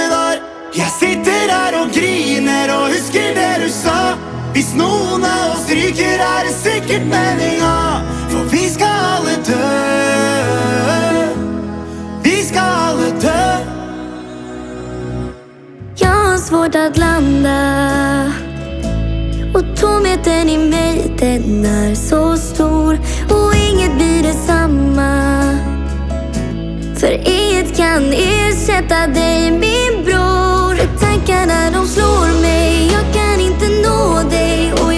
Жанр: Поп
# Pop